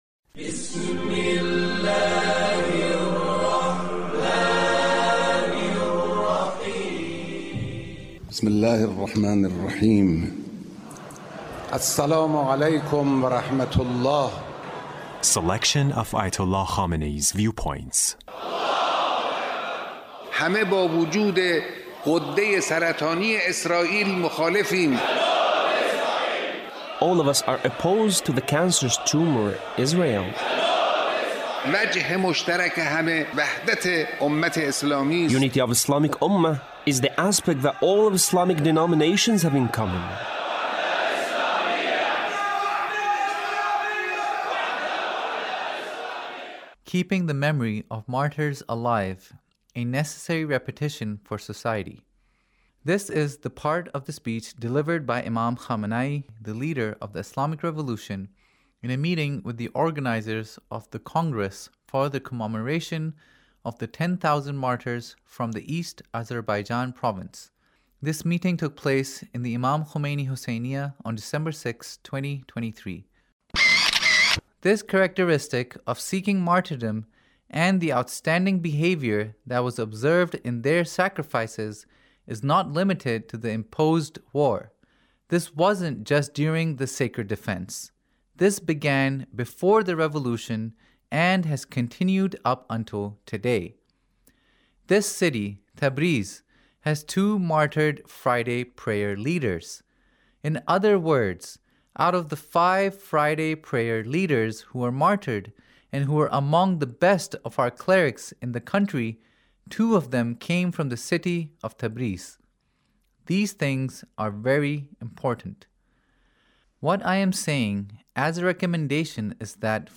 Leader's Speech, in a meeting with the organizers of the Congress for the Commemoration of the 10,000 Martyrs from the East Azerbaijan Province